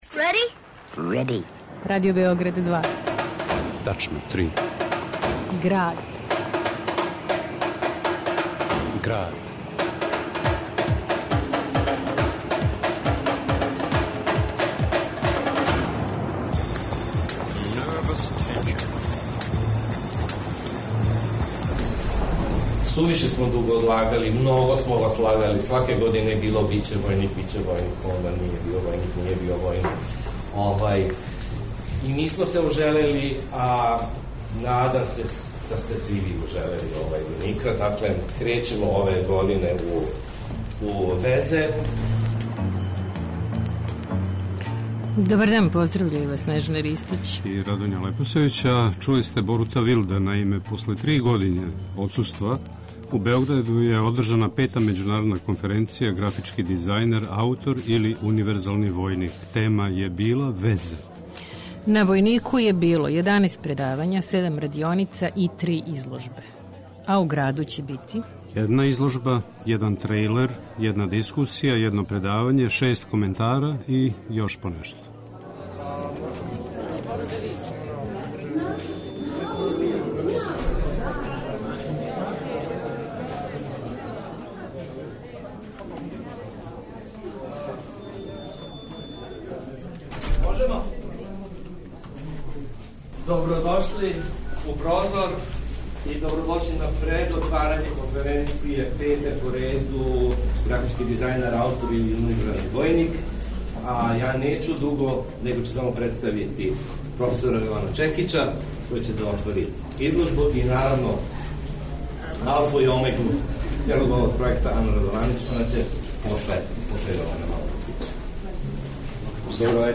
говоре учесници и организатори